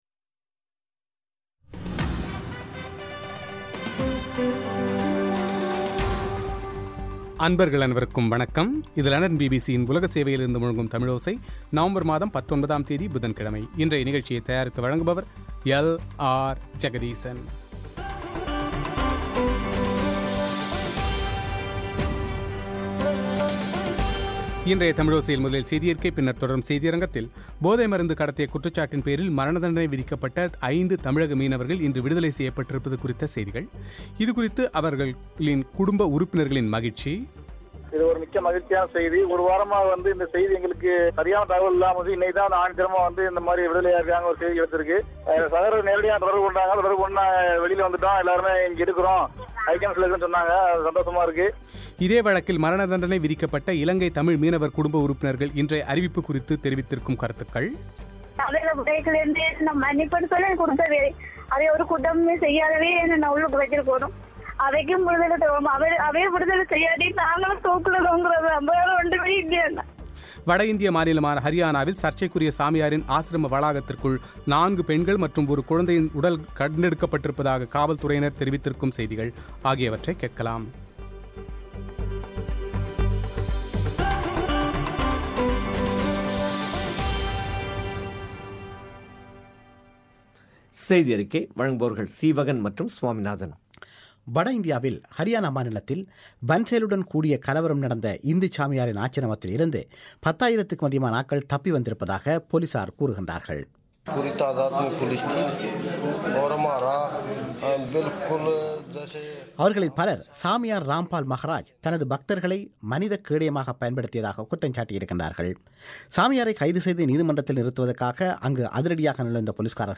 இந்த விடுதலை குறித்து தமிழகத்தைச் சேர்ந்த மத்திய அமைச்சர் பொன் ராதாகிருஷ்ணனின் பேட்டி;